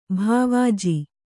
♪ bhāvāji